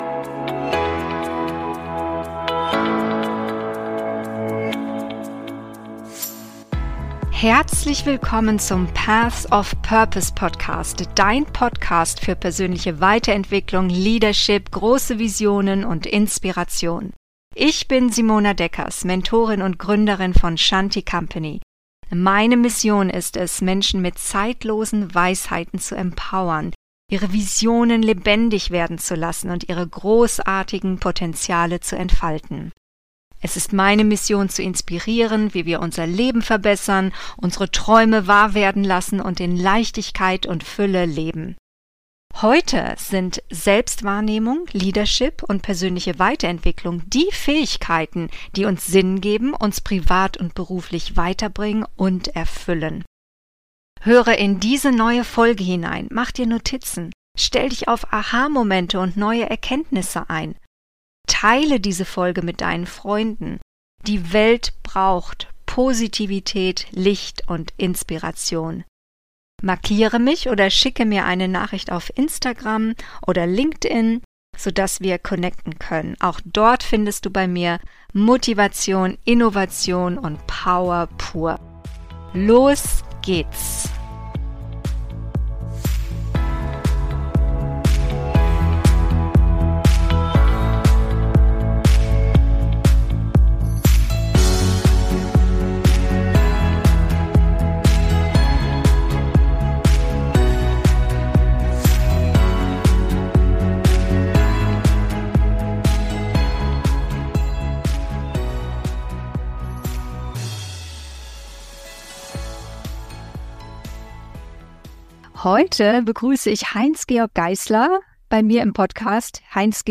Alles anders im Vertrieb: Herausforderungen und Chancen heute - Interview